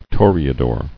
[tor·e·a·dor]